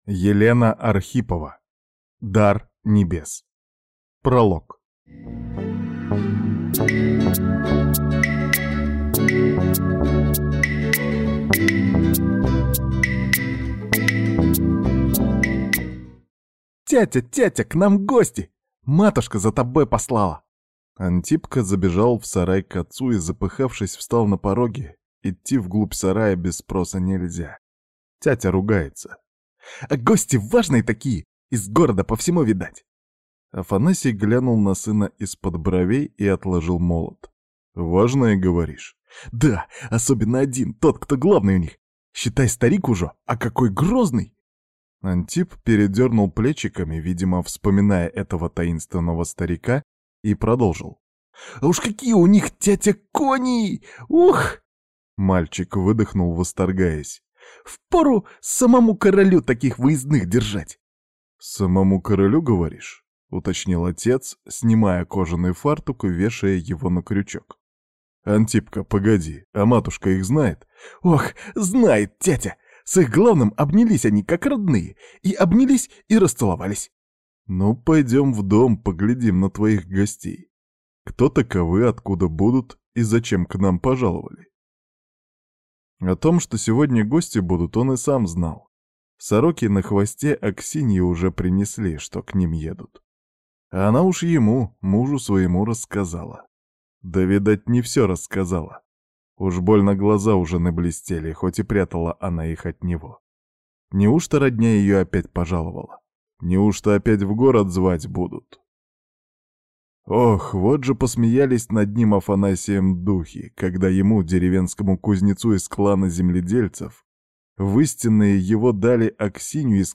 Аудиокнига Дар небес | Библиотека аудиокниг